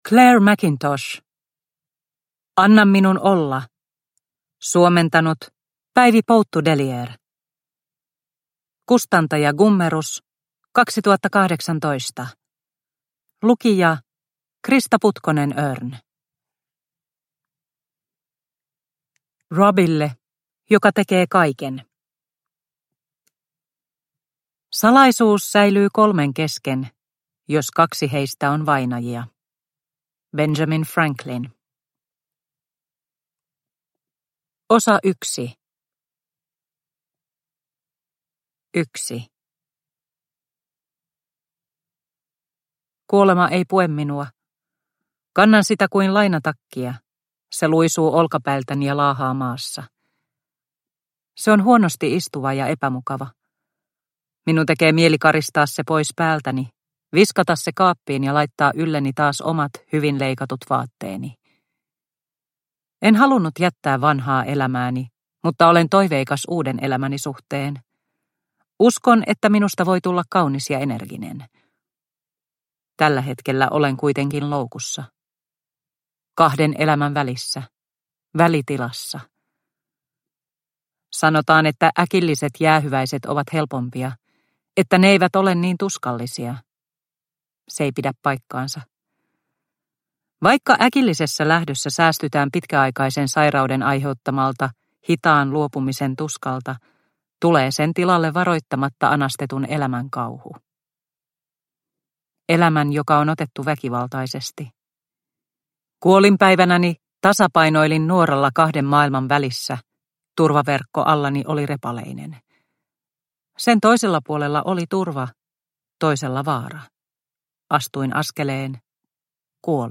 Anna minun olla – Ljudbok – Laddas ner